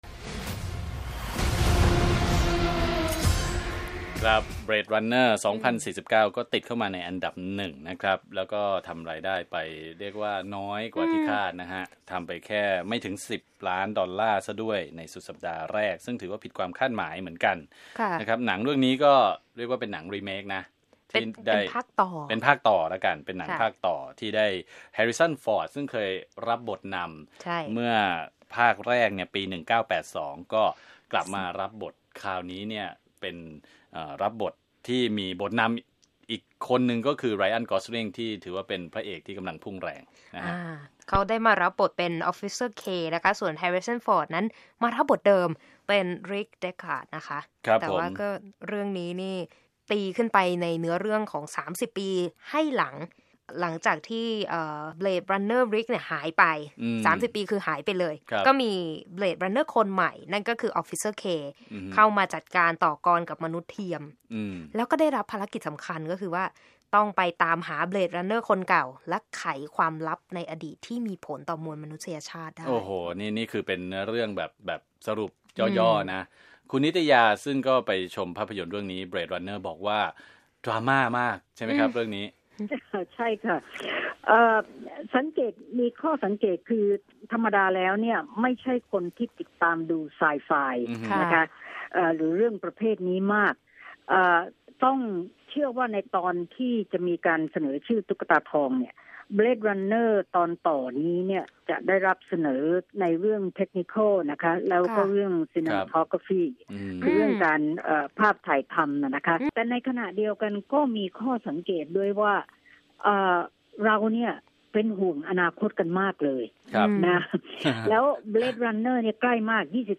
(รับฟังจากความเห็นผู้ดำเนินรายการ